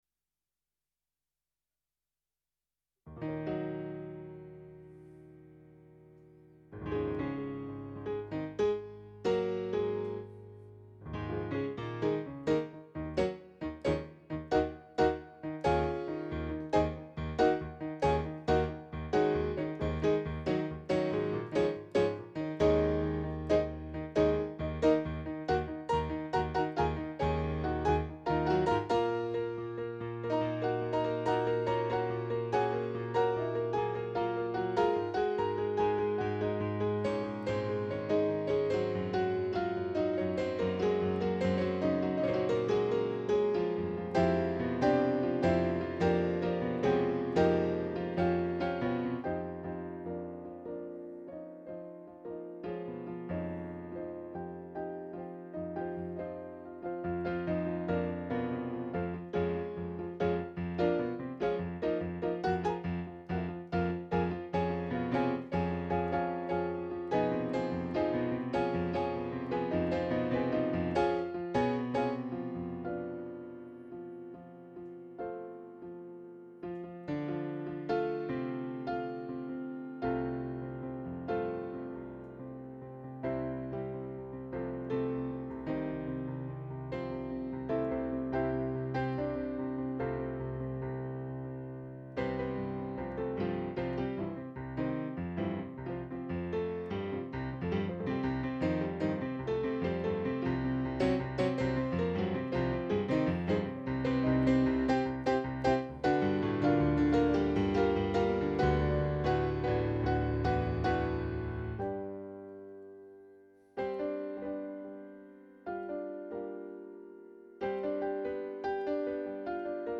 Improvisations réalisées entre juillet et novembre 2014.